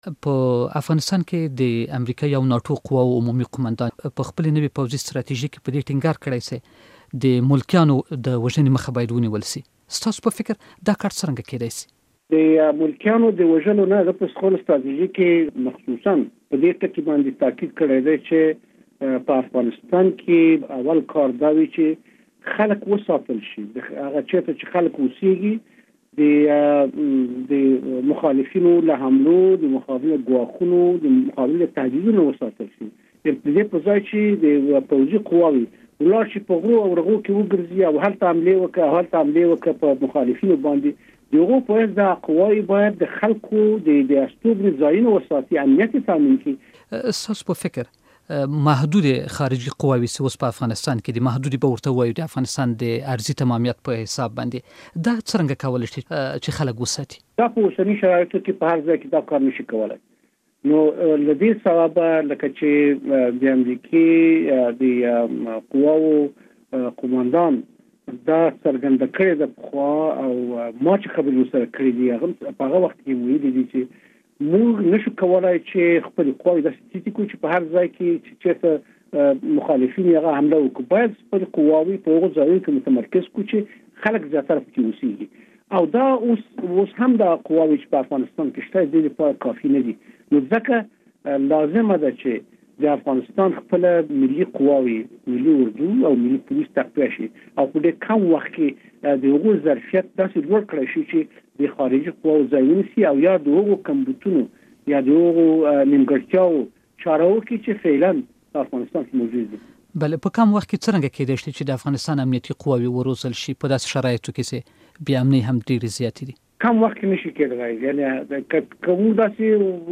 له علي احمد جلالي سره مرکه واورﺉ